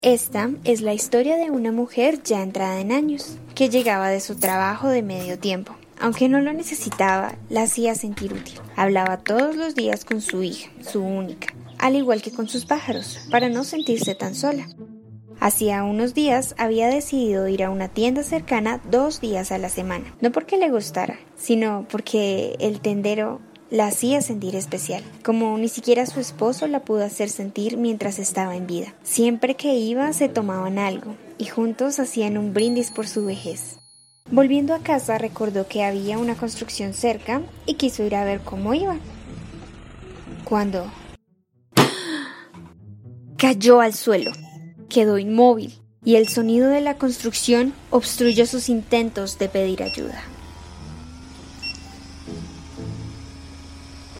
Contó con la participación intergeneracional de usuarios de la mencionada biblioteca.
Producción radial, Adultos mayores